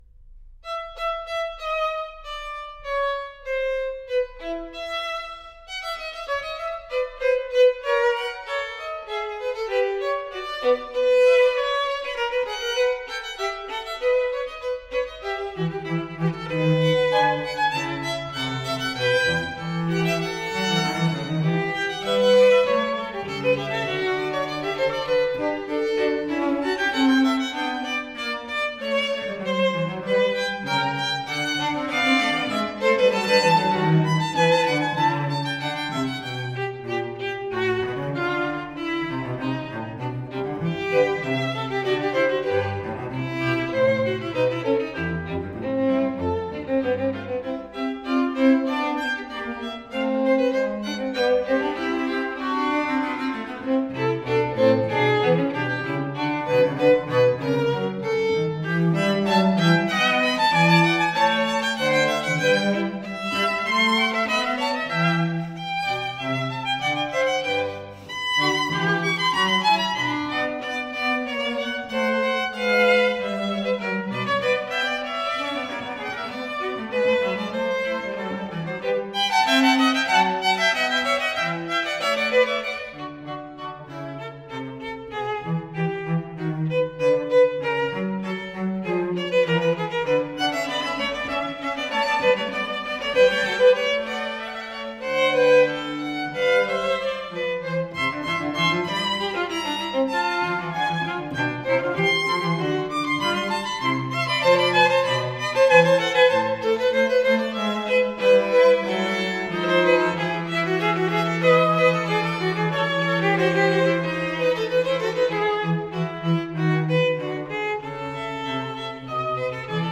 Soundbite Fugue
for 2 Violins and Violoncello
The Fugue is based on a chromatic theme.